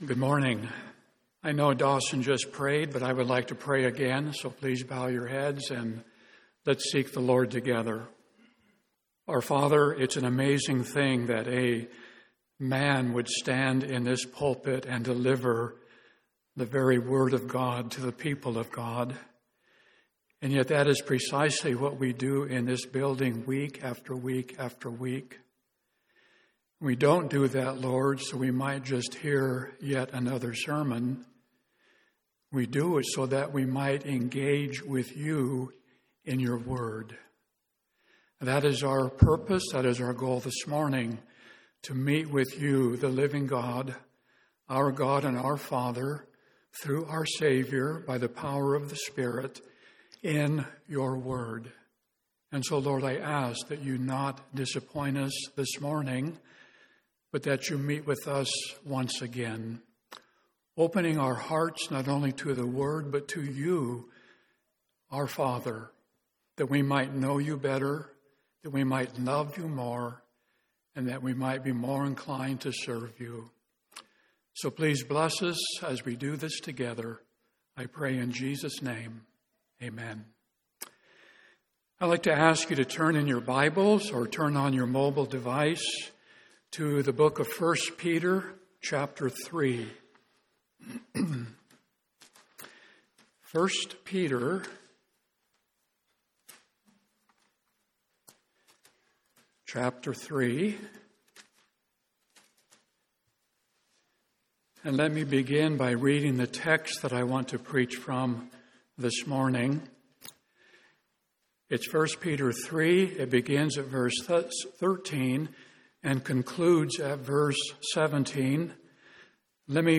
1 Peter — Audio Sermons — Brick Lane Community Church